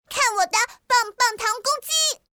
Game VO
Her inspired design is paired with a bright, bubbly, and crisp loli-style voice that vividly captures her youthful energy, playful spirit, and quick-witted charm.